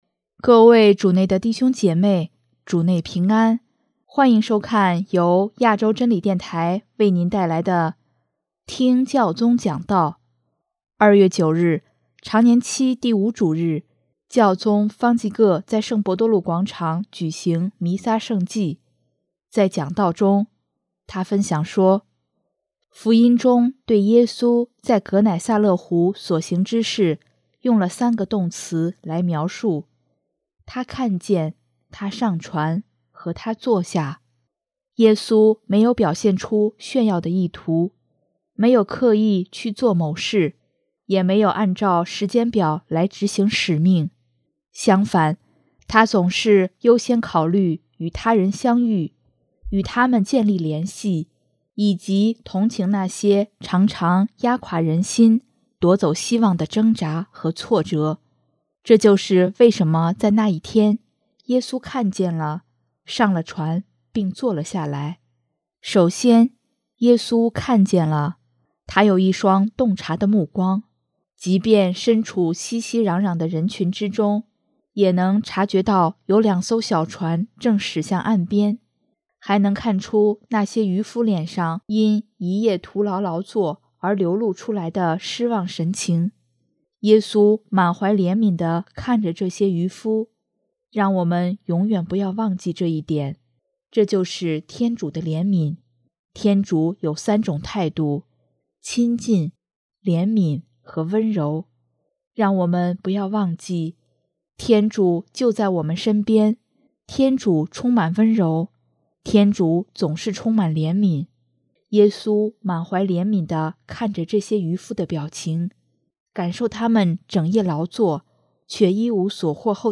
2月9日，常年期第五主日，教宗方济各在圣伯多禄广场举行弥撒圣祭，在讲道中，他分享说：